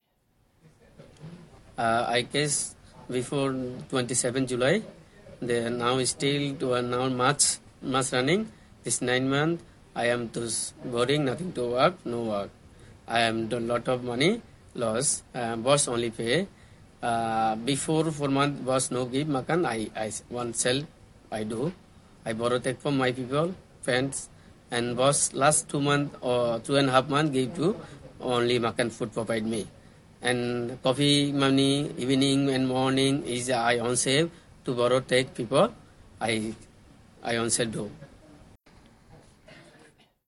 Here are some extracts from the debriefing interview.